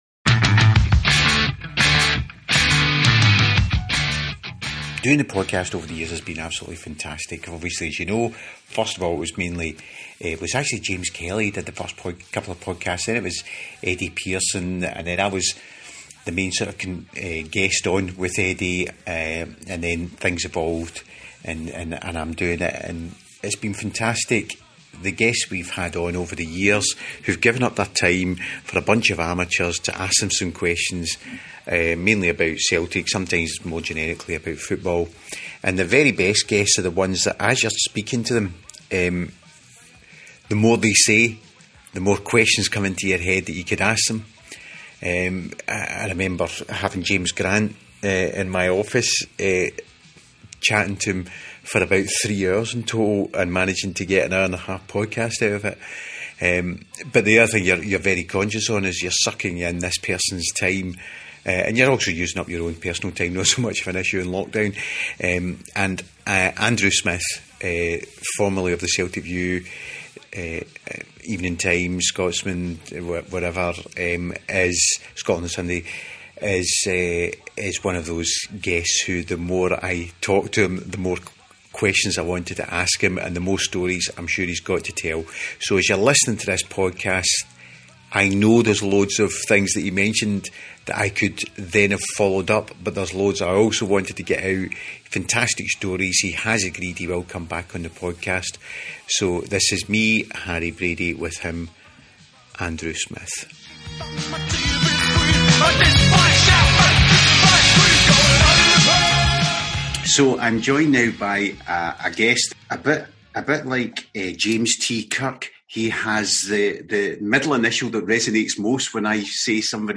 I love having guests on the podcast.